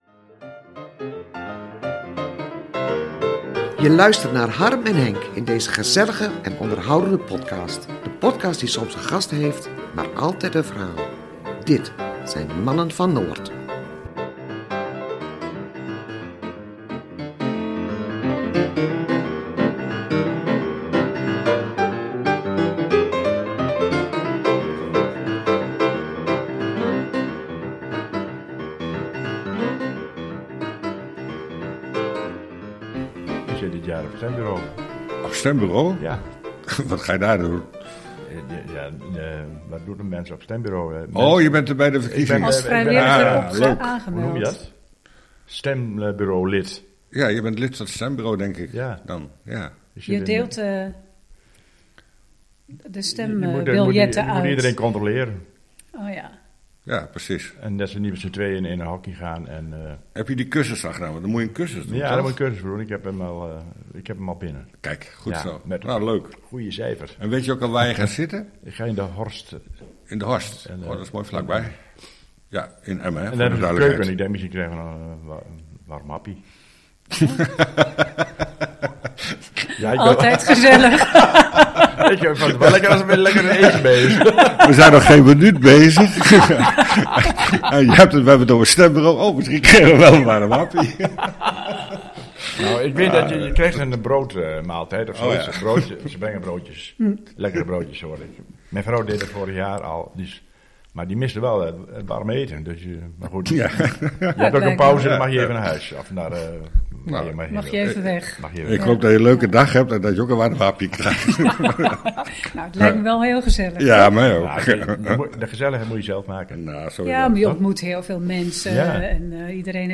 We gaan met hen in gesprek, en proberen te ontdekken wie zij zijn. Wat drijft hen om zich verkiesbaar te stellen en wat willen ze gaan betekenen voor Coevorden en het buitengebied?